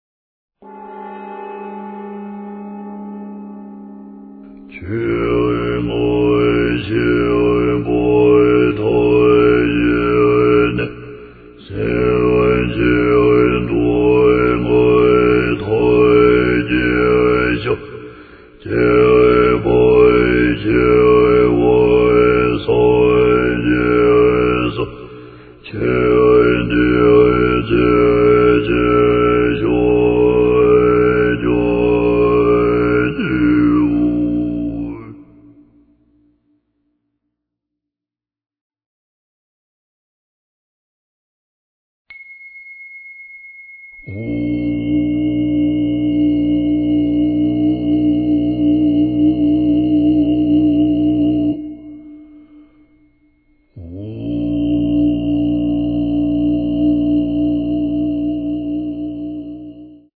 Die erstaunlich mächtige und volltönende Stimme
schafft eine außerordentlich harmonische Atmosphäre.